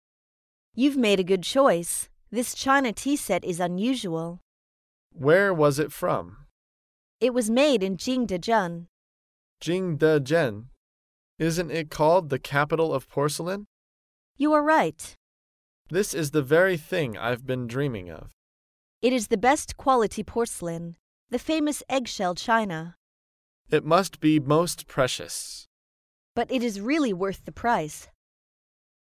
在线英语听力室高频英语口语对话 第278期:购买茶具的听力文件下载,《高频英语口语对话》栏目包含了日常生活中经常使用的英语情景对话，是学习英语口语，能够帮助英语爱好者在听英语对话的过程中，积累英语口语习语知识，提高英语听说水平，并通过栏目中的中英文字幕和音频MP3文件，提高英语语感。